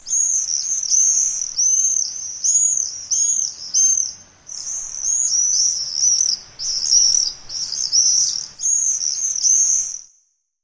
Погрузитесь в мир звуков стрижей – их звонкое щебетание напомнит о теплых летних днях.
Короткий звук с визгом стрижа